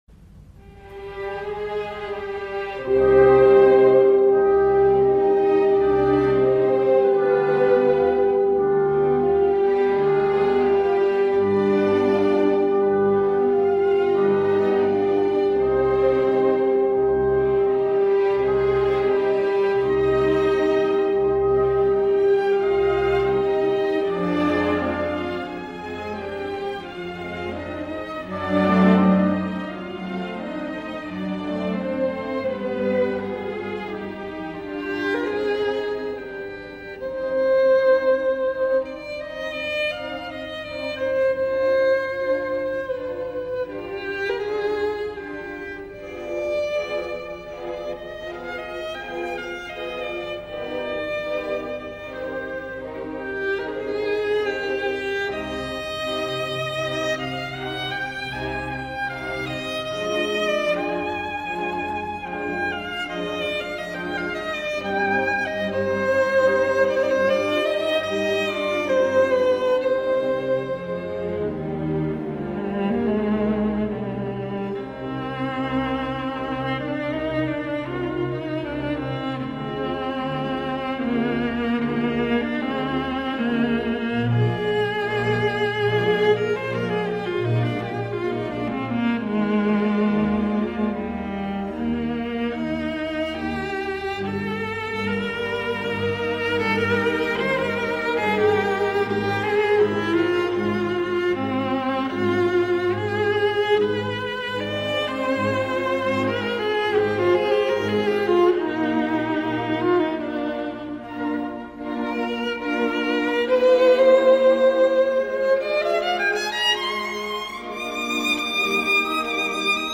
• J'ai une belle voix grave
• Je suis plus grand et plus costaud que mon petit frère le violon
mozart-sinfonia-concertante-pour-violon-alto-k364-andante-part.mp3